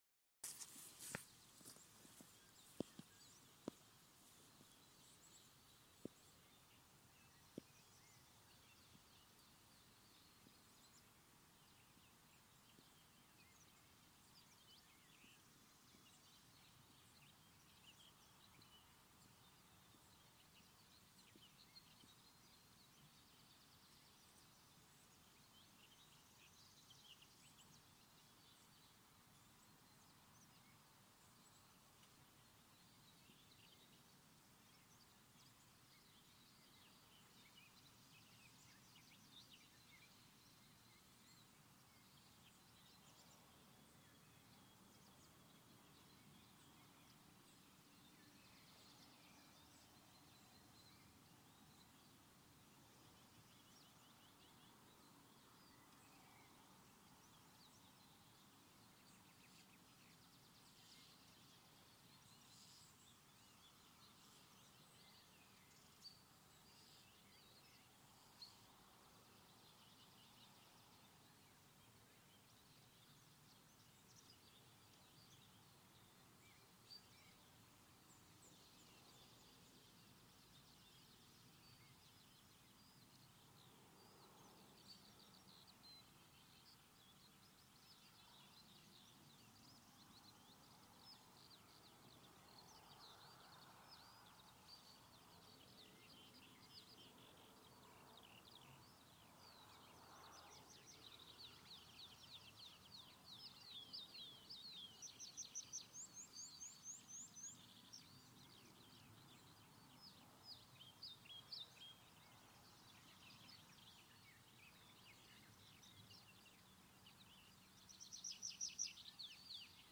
Putns (nenoteikts), Aves sp.